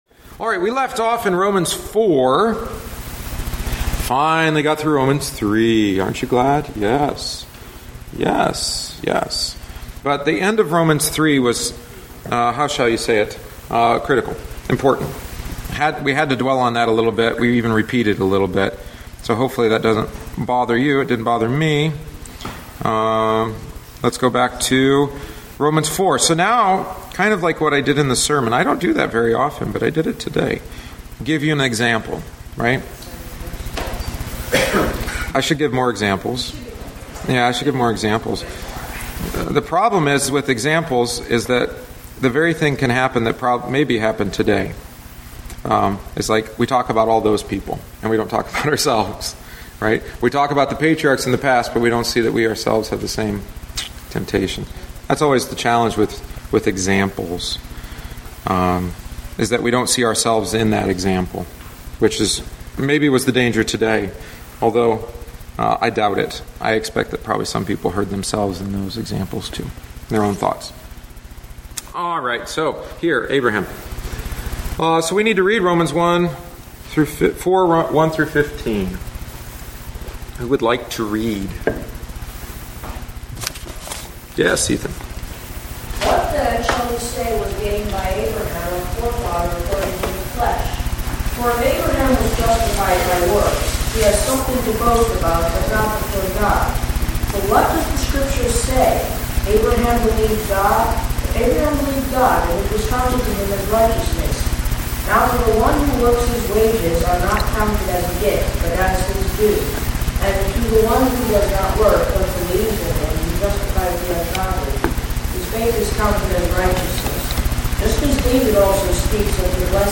The following is the tenth week’s lesson. We consider the examples of Abraham and David with an excursus on faith.